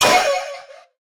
Minecraft Version Minecraft Version snapshot Latest Release | Latest Snapshot snapshot / assets / minecraft / sounds / mob / allay / hurt1.ogg Compare With Compare With Latest Release | Latest Snapshot
hurt1.ogg